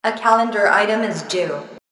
دانلود آهنگ موبایل 3 از افکت صوتی اشیاء
جلوه های صوتی